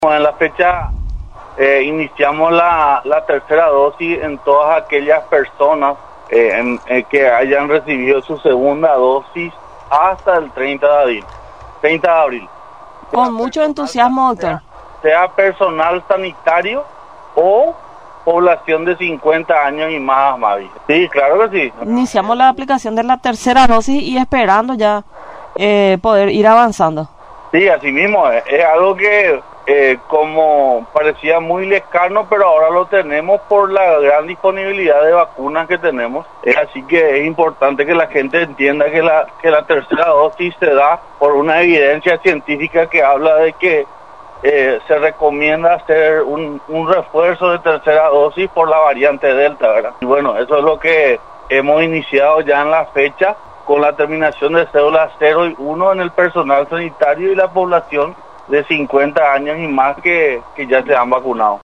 El Ministerio de Salud Pública y Bienestar Social (MSPYBS) inició este lunes, la aplicación de la tercera dosis a personas de más de 50 años de edad y al personal de blanco, quienes recibieron la segunda dosis hasta el 30 de abril, informó a Radio Nacional del Paraguay, el director de la Undécima Región Sanitaria, doctor Roque Silva.